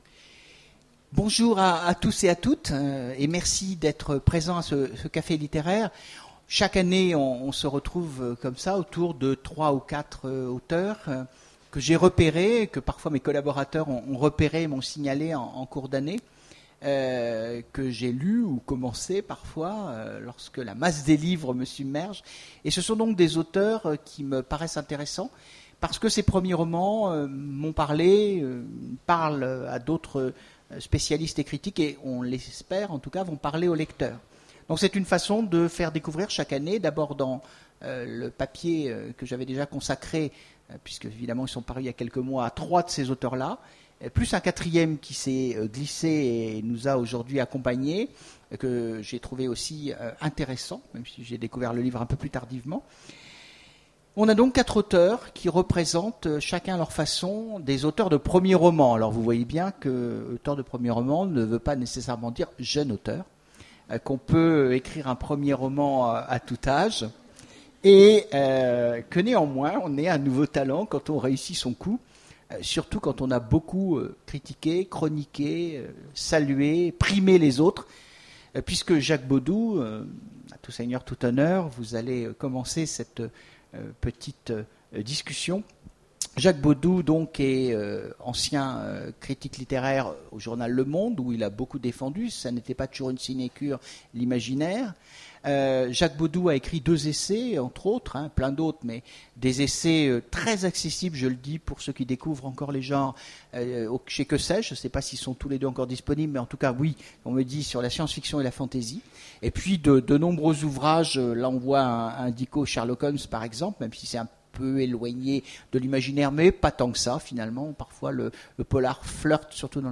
Imaginales 2015 : Conférence Premiers romans